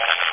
第三电台小噪音 " Jp电台creak3
描述：AM收音机静电引起的吱吱声很小。使用特温特大学在线无线电接收器录制。 可用于打击乐，故障或电子音乐。